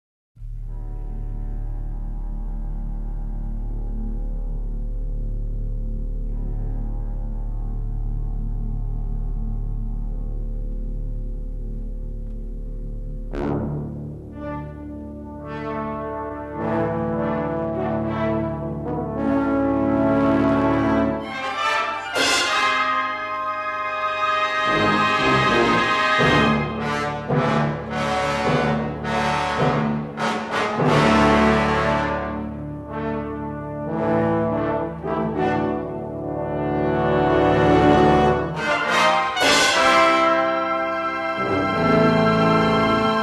sole surviving mono mixdown safety master